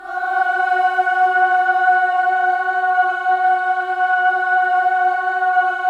VOWEL MV12-R.wav